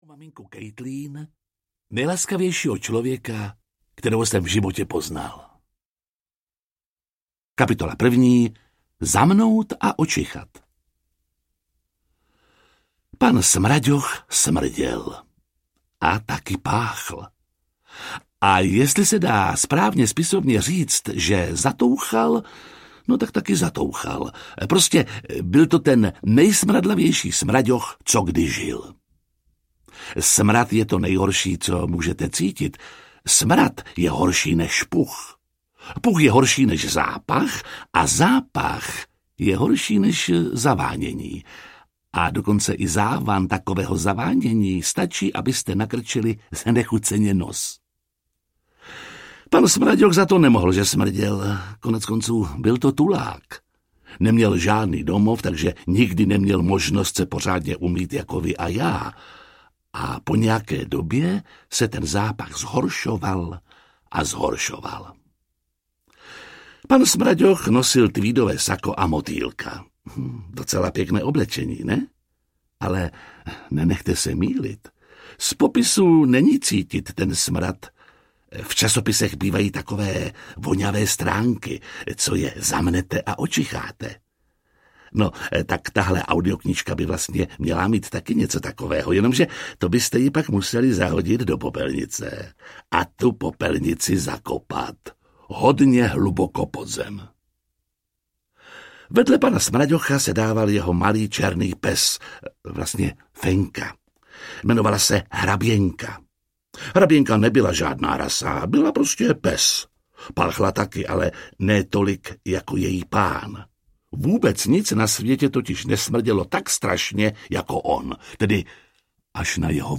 Pan Smraďoch audiokniha
Ukázka z knihy
• InterpretJiří Lábus